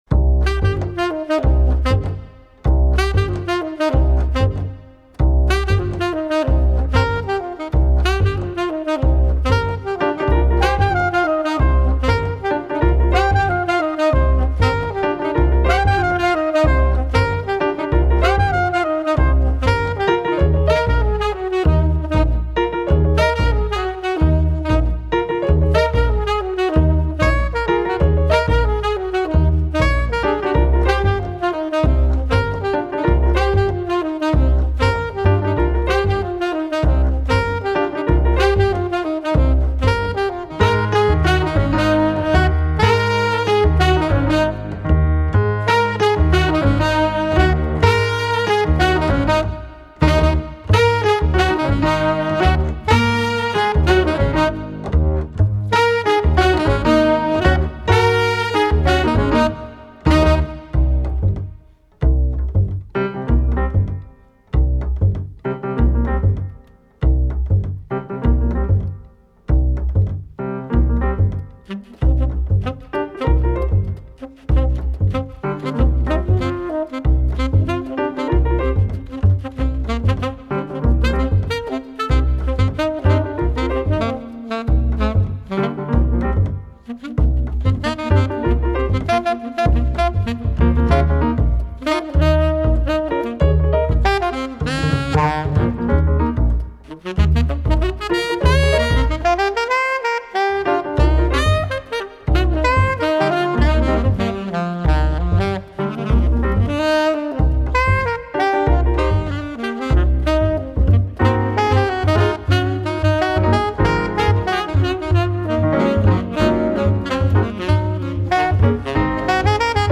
bass
tenor sax